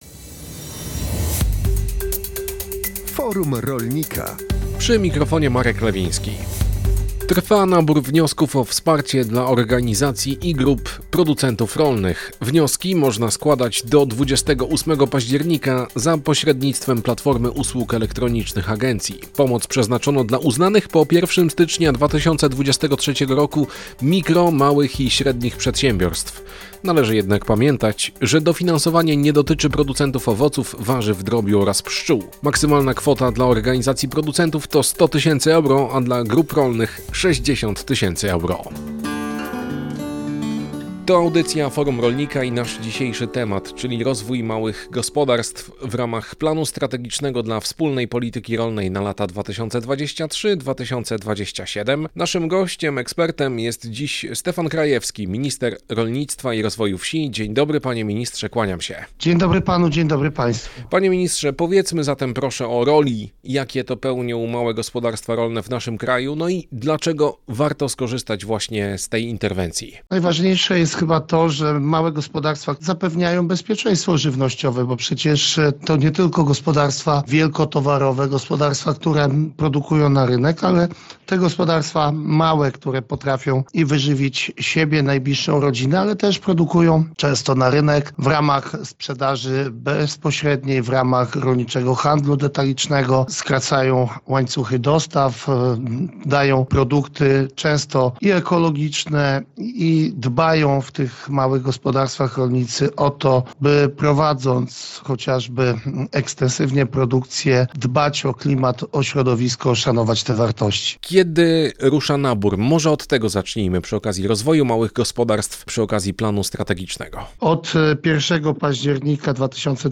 Audycja o tematyce rolnej „Forum Rolnika” emitowana jest na antenie Radia Kielce w środy po godz. 12.